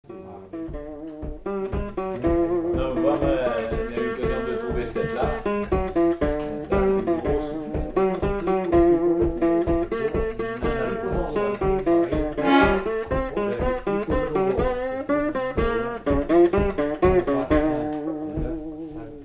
Mémoires et Patrimoines vivants - RaddO est une base de données d'archives iconographiques et sonores.
Tampon (Le)
Instrumental
danse : polka